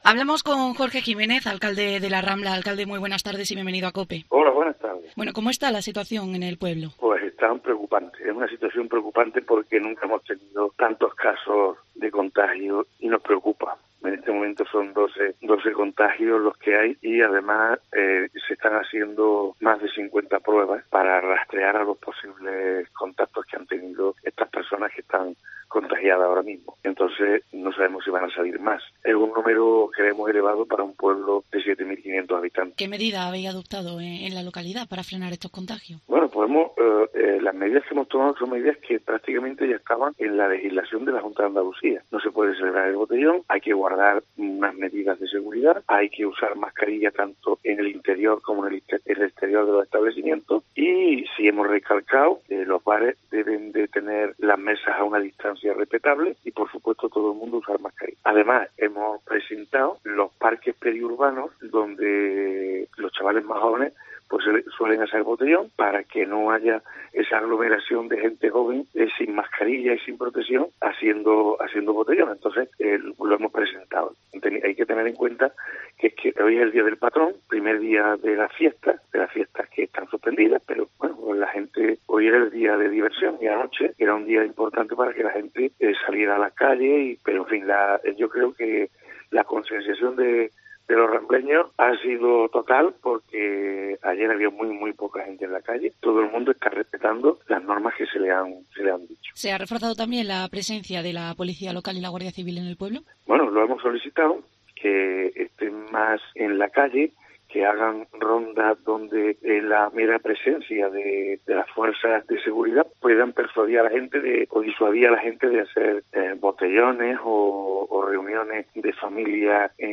El alcalde de La Rambla, Jorge Jiménez, ha pasado hoy por los micrófonos de COPE, donde ha explicado la situación en la que se encuentra la localidad cordobesa, en la que actualmente hay 12 casos positivos.